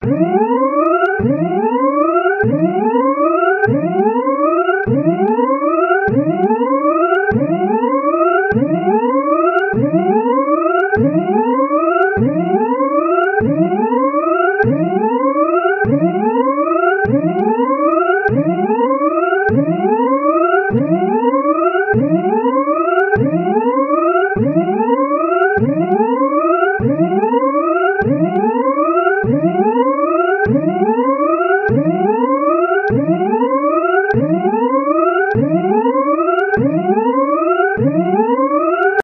少し低めのブザー音。